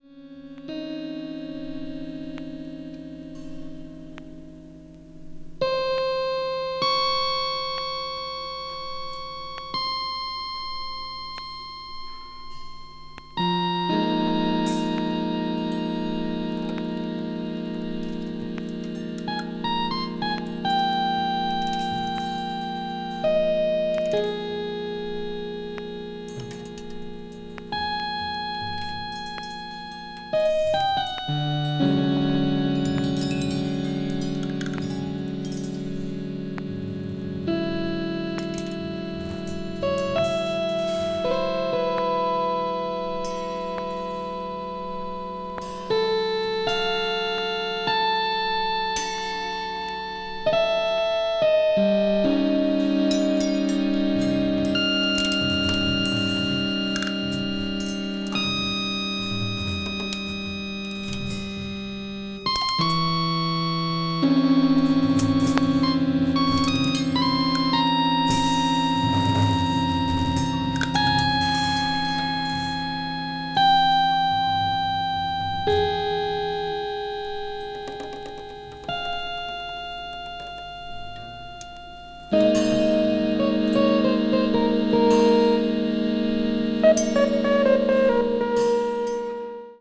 A面の先頭部分に1cm程のスリキズがあり、断続的なプチノイズが入ります。
A(ノイズ部分):